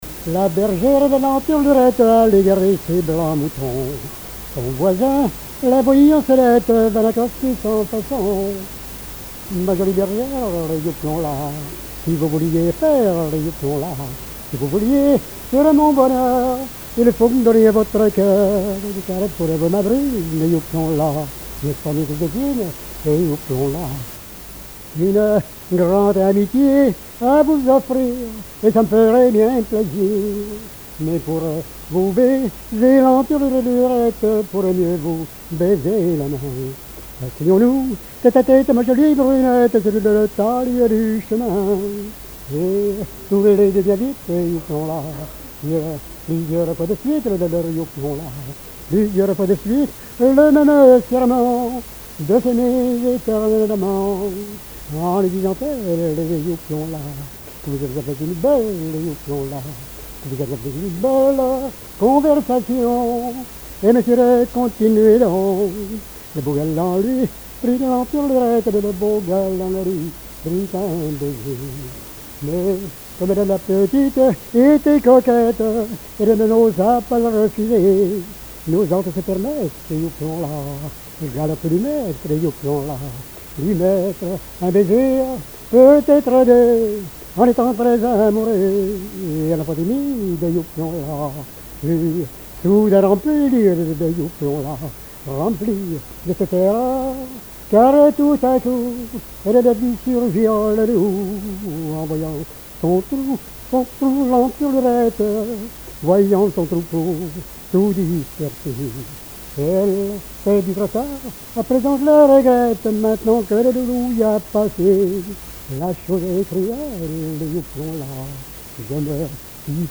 Genre strophique
Programme de chansons populaires
Pièce musicale inédite